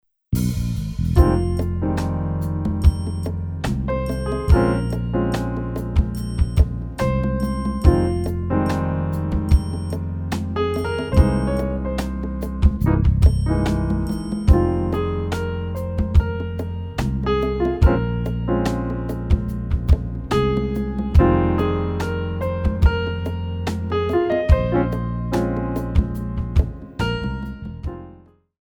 4 bar intro
slow ballad
Contemporary  / Stretch